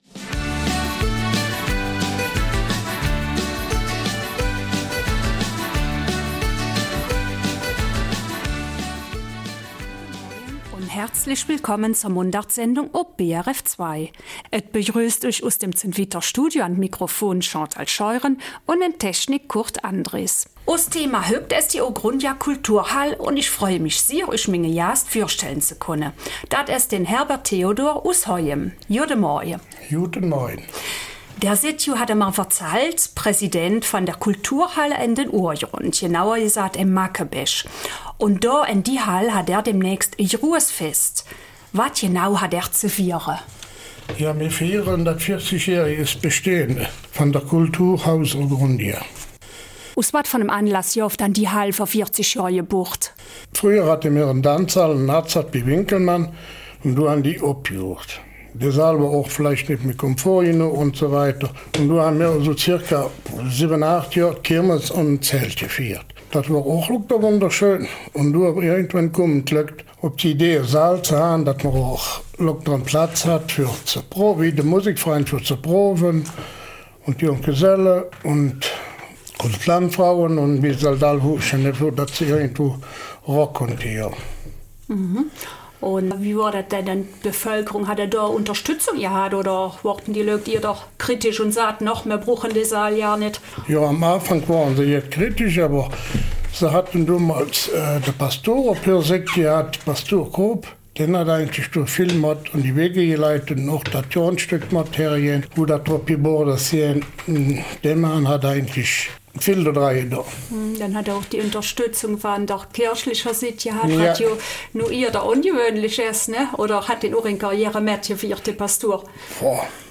Eifeler Mundart: 40 Jahre Ourgrundia